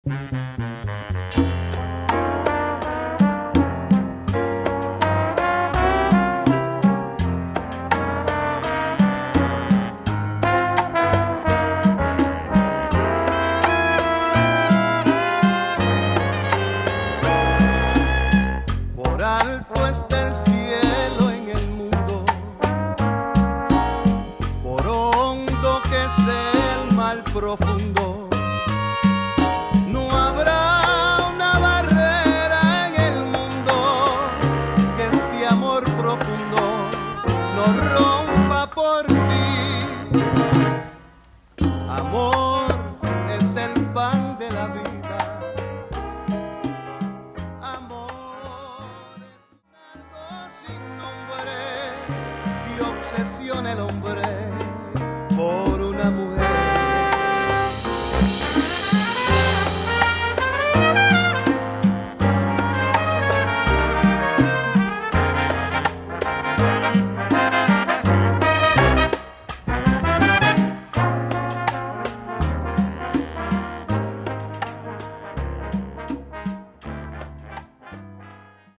Latin jazz
Category: combo (tentet)
Style: bolero
Solos: vocal